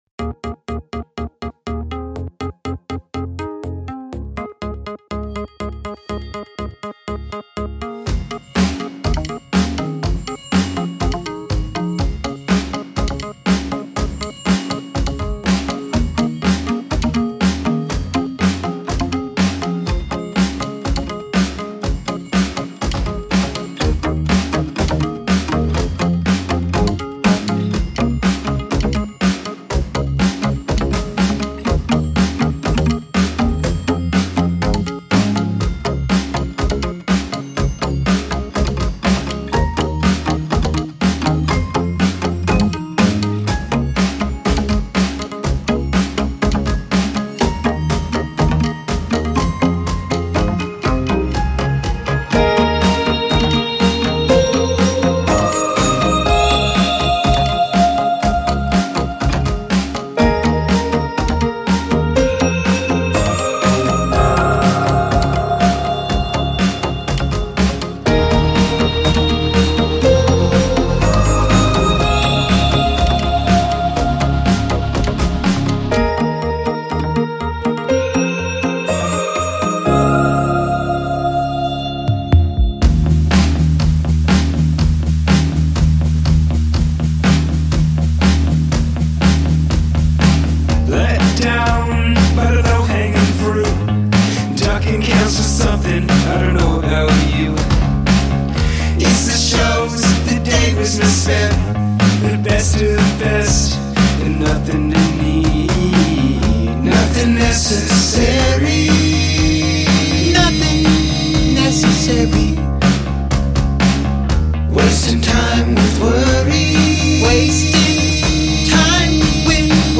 electronica-projekt